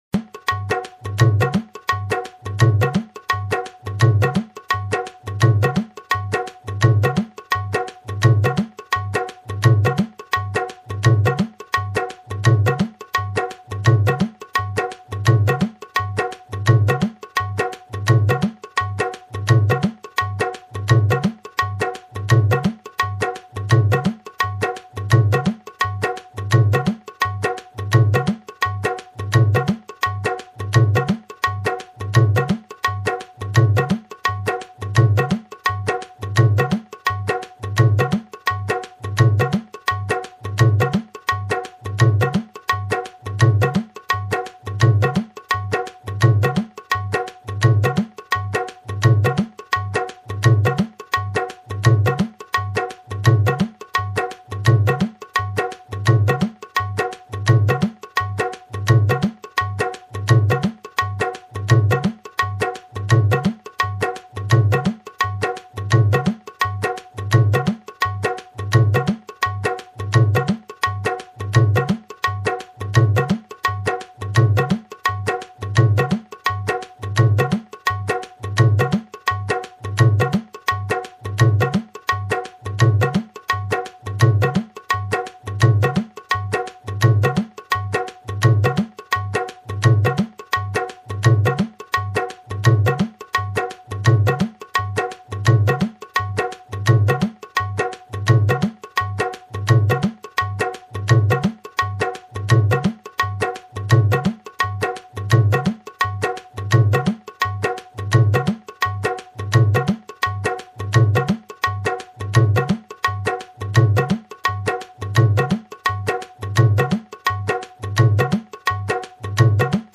Downloading dholak 2 loop Please wait........